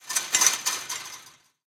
ambienturban_9.ogg